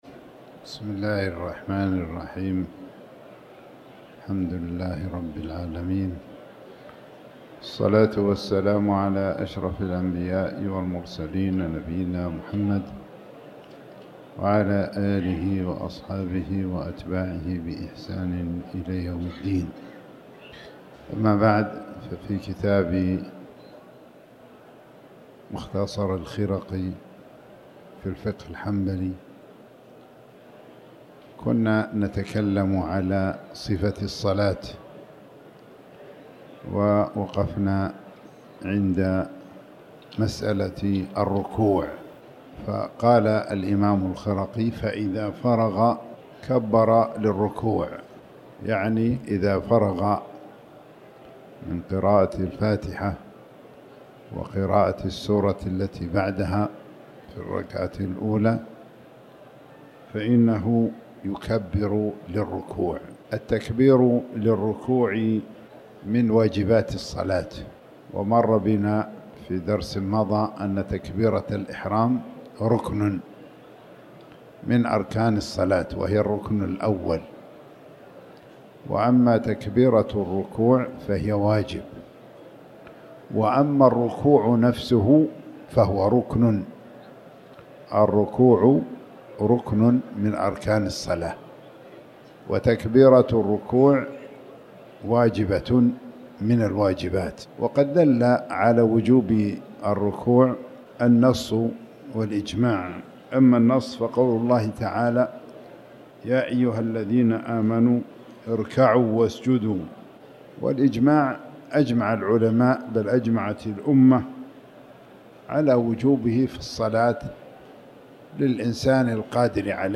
تاريخ النشر ٢٠ رجب ١٤٤٠ هـ المكان: المسجد الحرام الشيخ